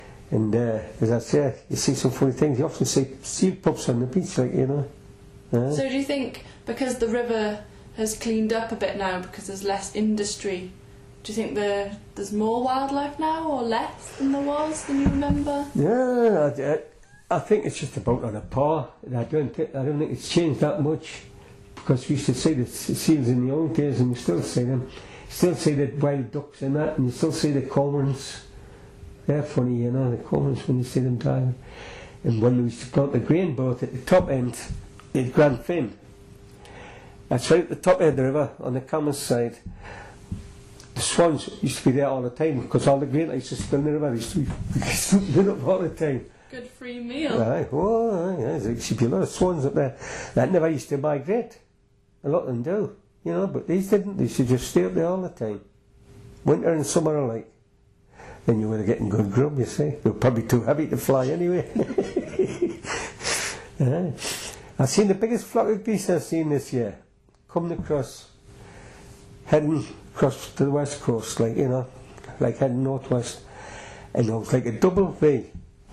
These sound files are extracts (short, edited pieces) from longer oral history interviews preserved by Northumberland Archives.